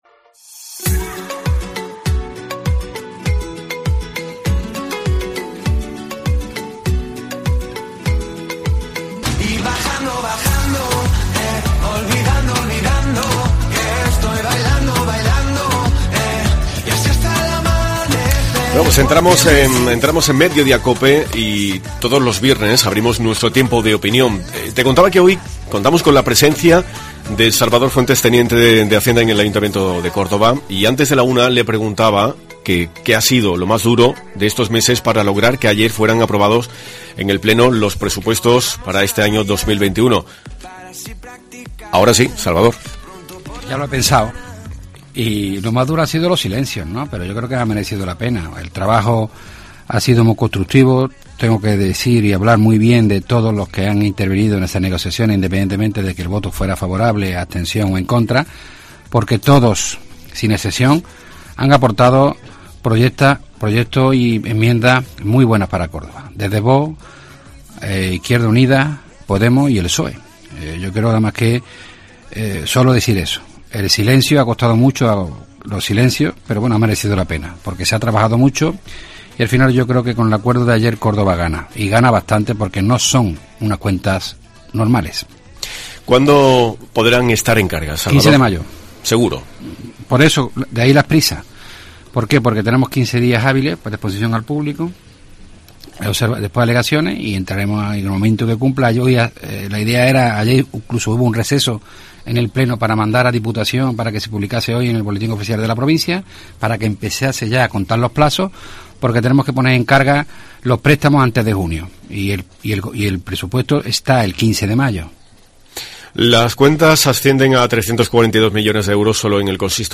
El teniente de Hacienda del Ayuntamiento de Córdoba, ha visitado los estudios de COPE, un día después de ser aprobados los presupuestos para 2021